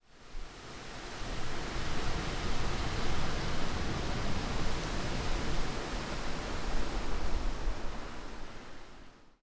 Gust 5.wav